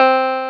CLAVI6 C4.wav